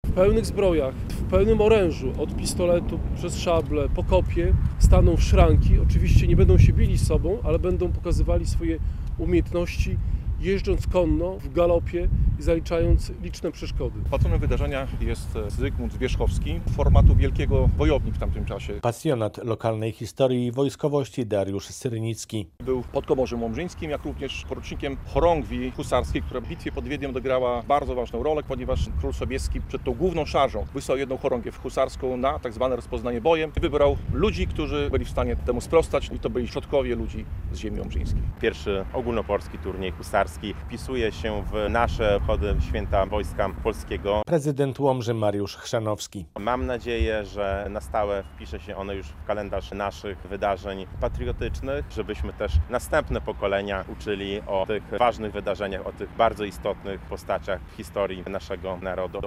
Husarze zaprezentują swoje umiejętności podczas turnieju w Łomży - relacja